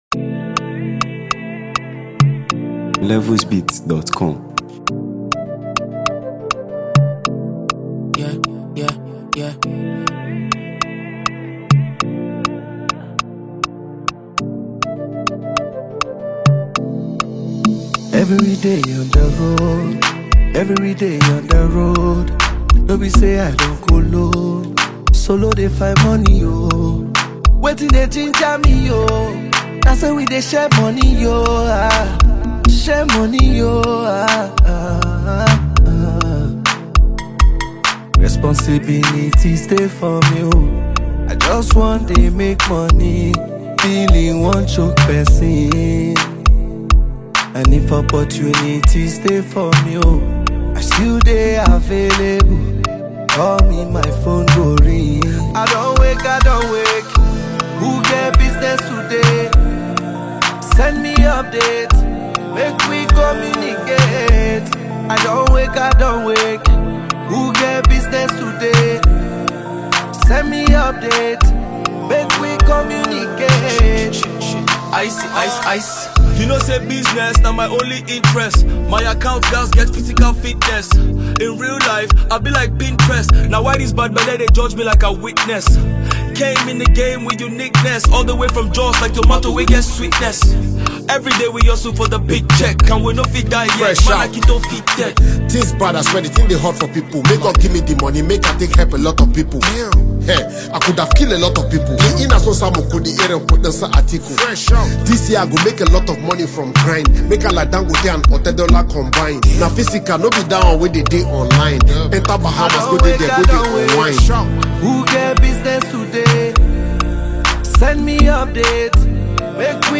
” a dynamic and infectious track
blending vibrant melodies with electrifying energy.